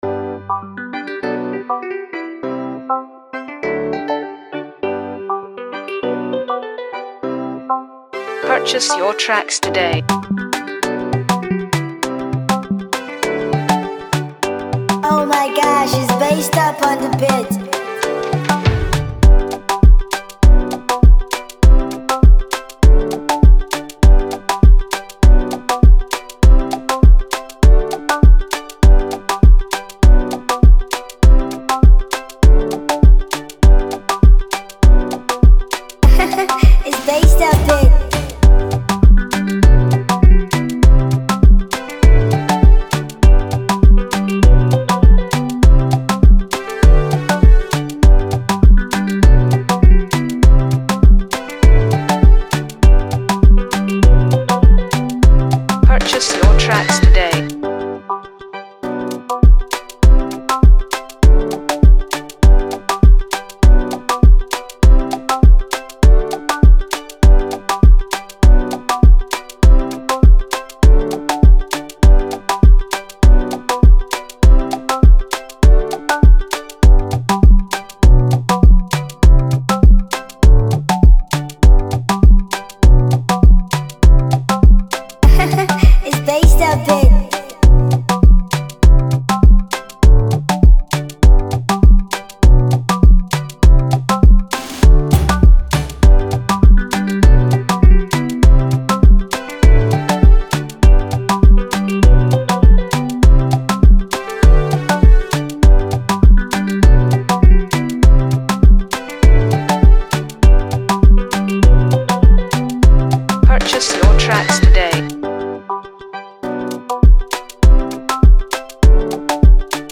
Download instrumental mp3 below…